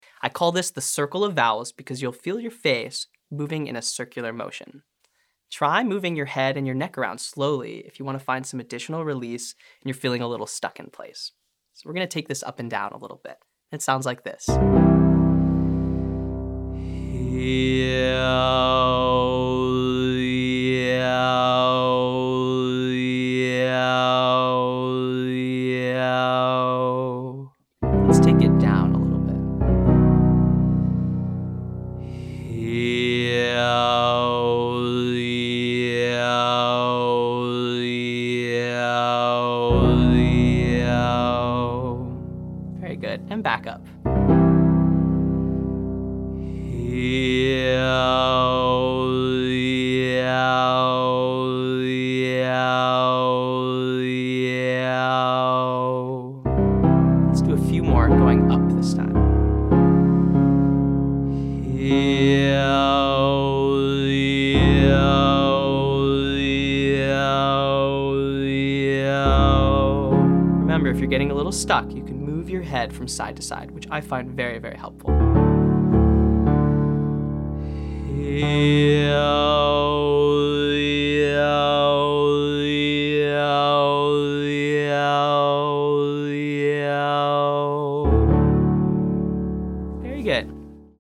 The 5 main vowels eee, ooo, aye, oh, and ah which are formed with our tongue and lips.
• Circle of Vowels